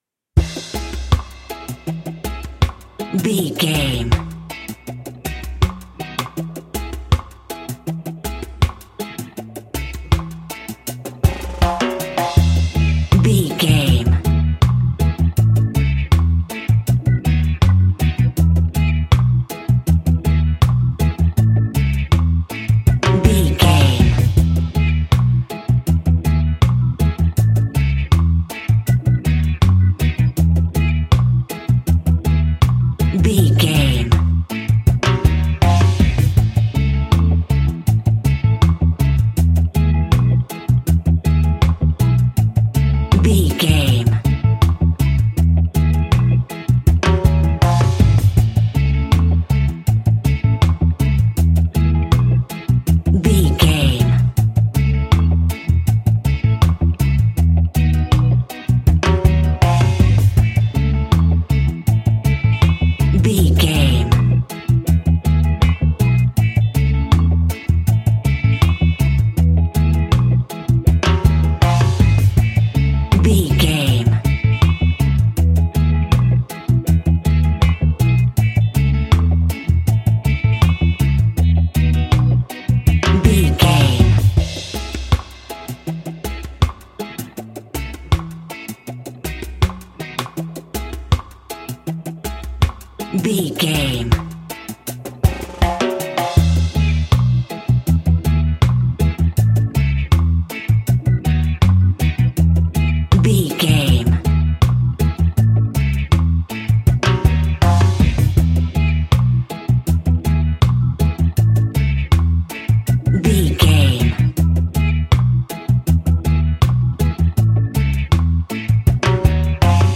Classic reggae music with that skank bounce reggae feeling.
Ionian/Major
dub
laid back
chilled
off beat
drums
skank guitar
hammond organ
percussion
horns